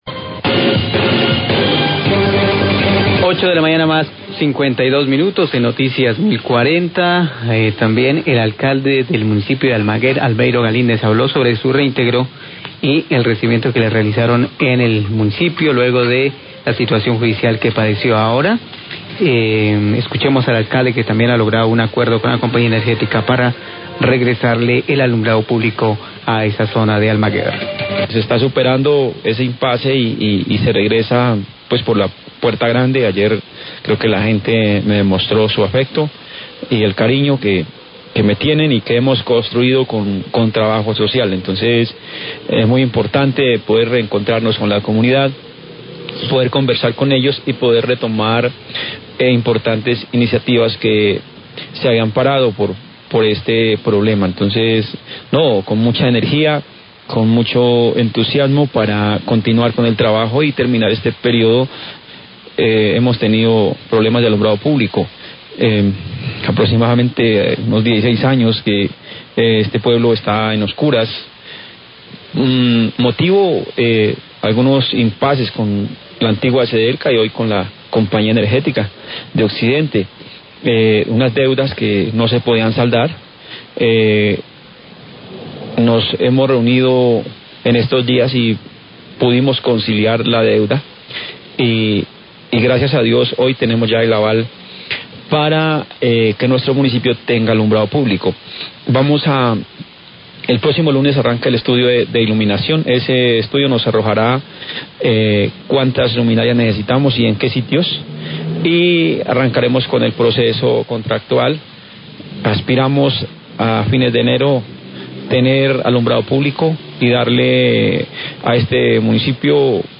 Radio
Alcalde de Almaguer, Albeiro Galindez, habla del acuerdo alcanzado con la Compañía Energética para restablecer el servicio de energía para el alumbrado público tras conciliar la deuda con la empresa. Se espera que en Enero se tenga el servicio.